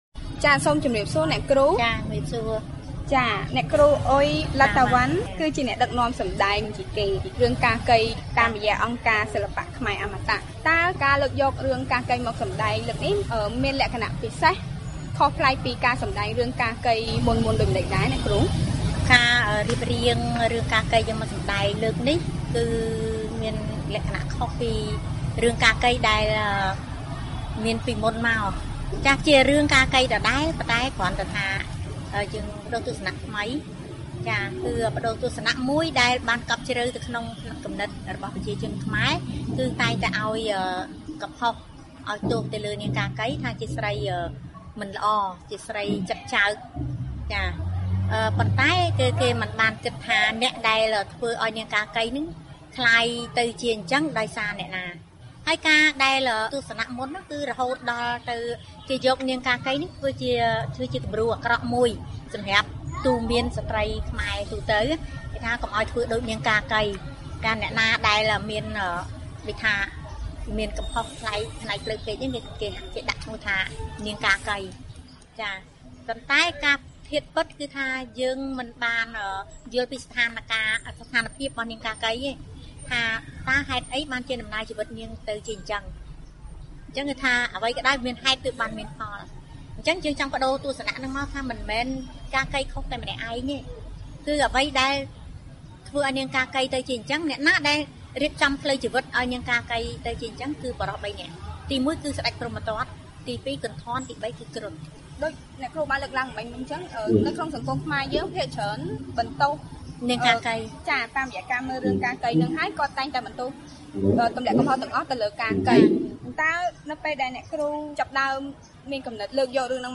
បទសម្ភាសន៍ VOA៖ តួអង្គកាកីទទួលបានអយុត្តិធម៌ផ្លូវភេទ